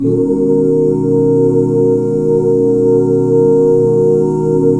BMAJ7 OOO -R.wav